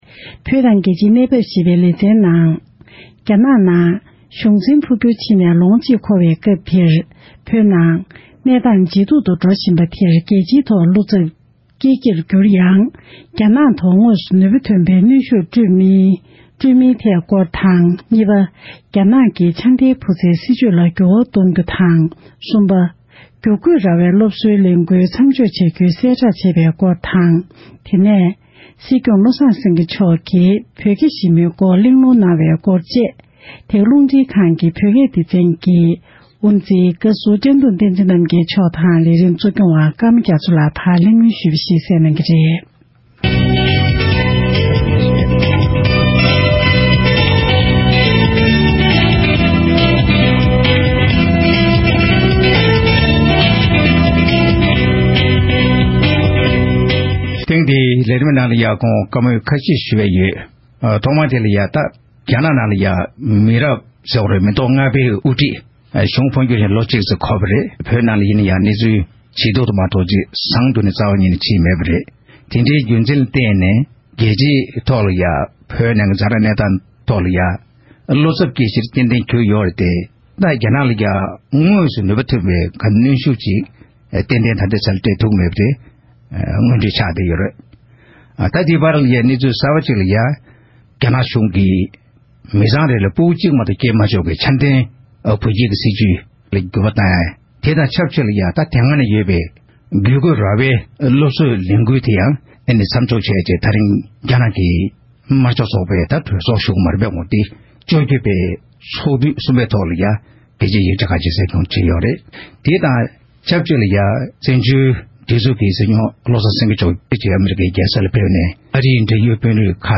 རྒྱ་ནག་ནང་གི་སྲིད་ཇུས་འགྱུར་བ་དང་བོད་ནང་གི་གནས་སྟངས་སྐོར་དཔྱད་གླེང༌།